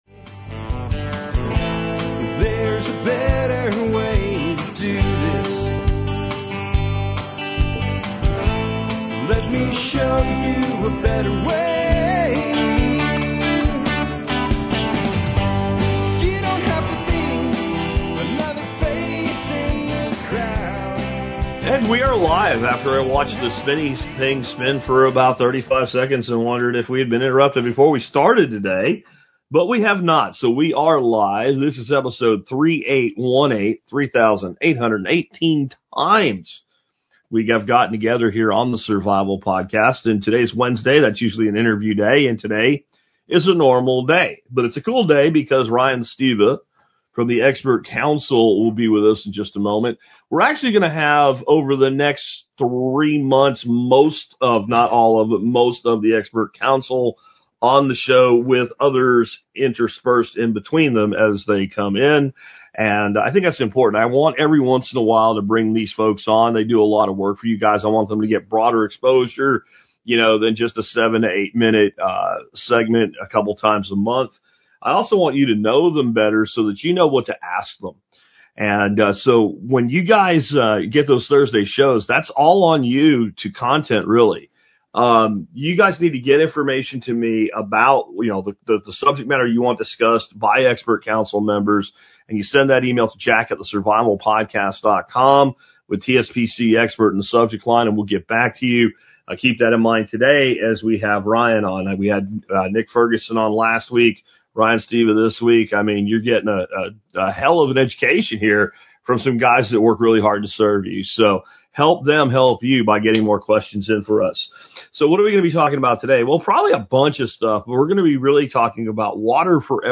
The survival podcast is the only online talk show 100% dedicated to modern survivalism, sustainability, alternative energy & thriving in changing economic times.